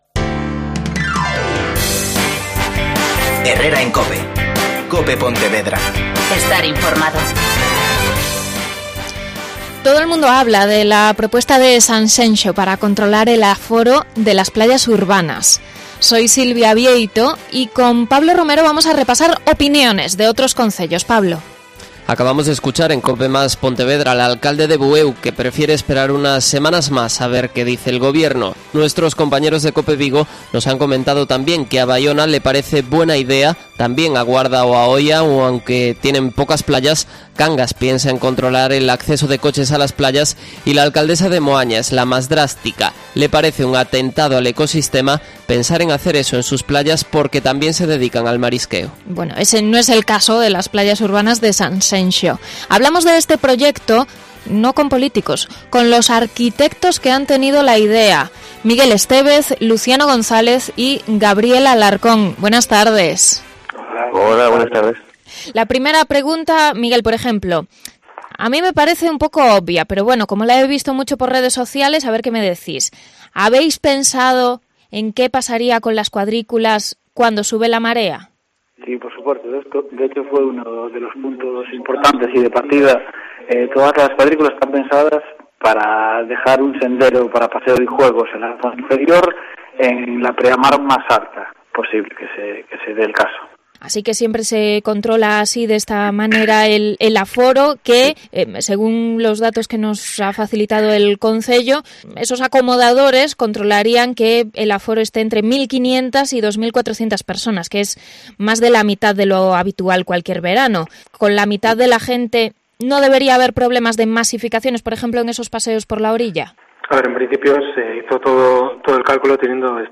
Entrevista a los arquitectos responsables del proyecto para playas de Sanxenxo